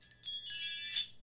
Index of /90_sSampleCDs/Roland - Rhythm Section/PRC_Asian 2/PRC_Windchimes
PRC CHIME02L.wav